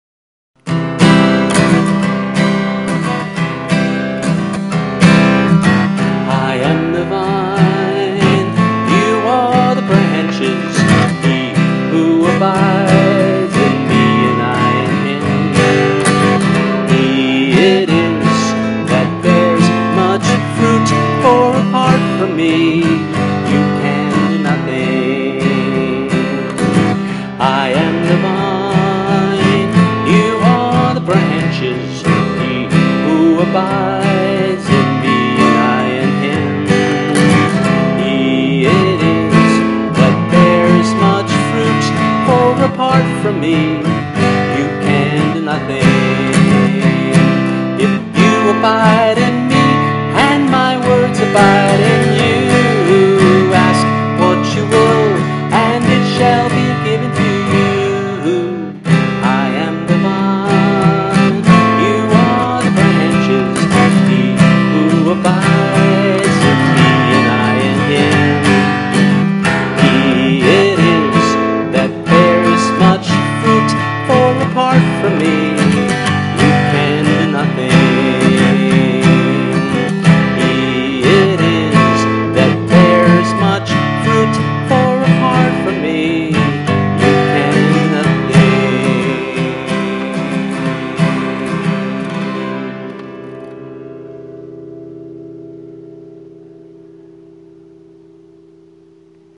[MP3, with guitar]    [MP3, voice only]